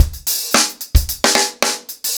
TupidCow-110BPM.9.wav